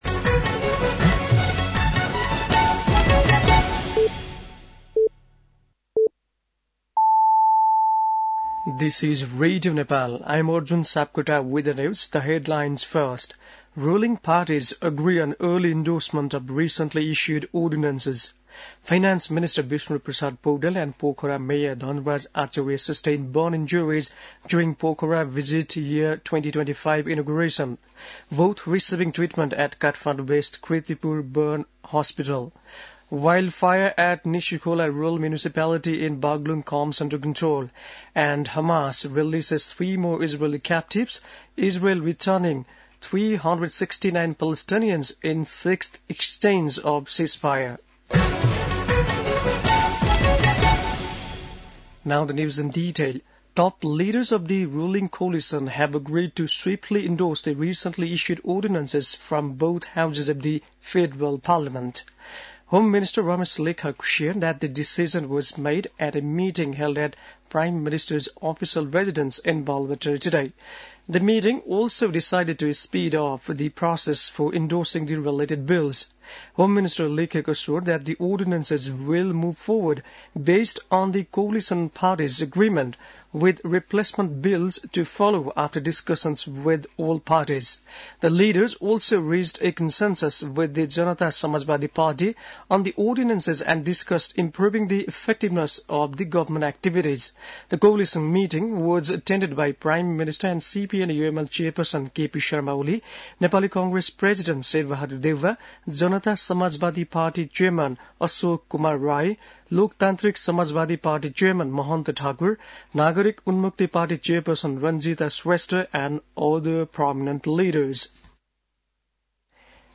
बेलुकी ८ बजेको अङ्ग्रेजी समाचार : ४ फागुन , २०८१